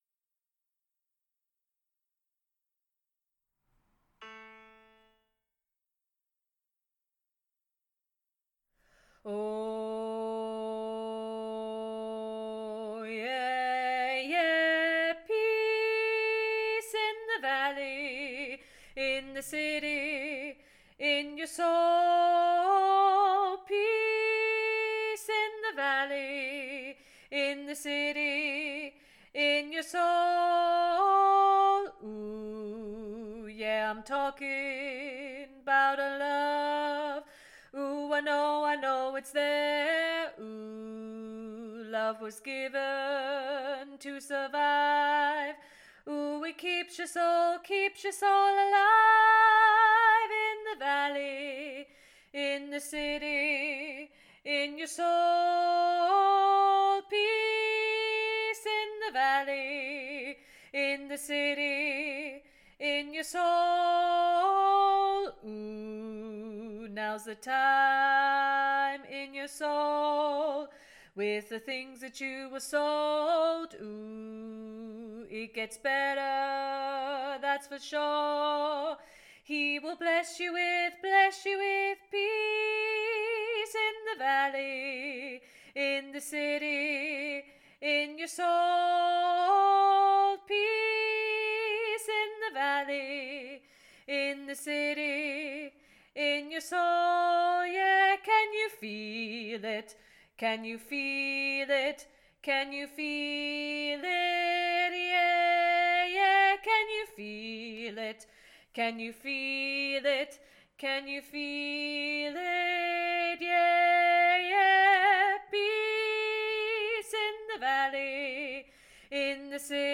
Peace 3VG Revamp BASS ONLY
Peace-3VG-Revamp-BASS-ONLY.mp3